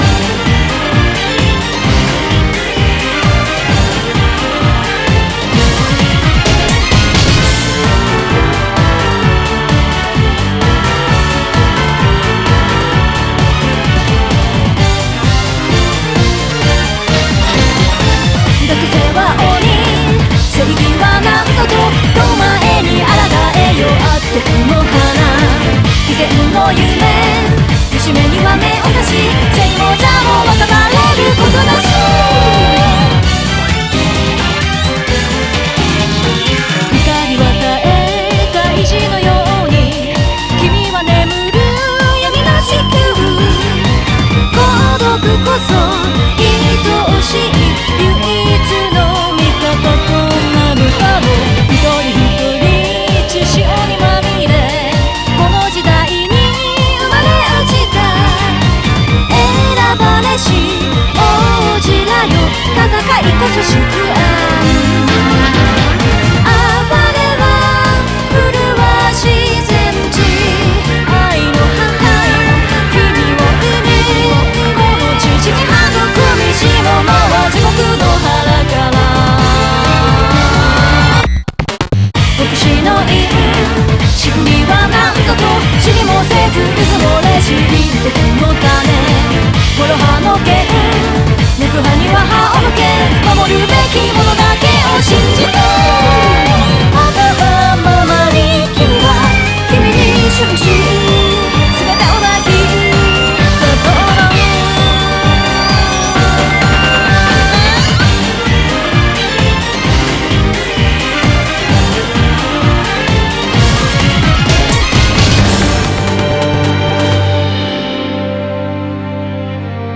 BPM130
Audio QualityPerfect (High Quality)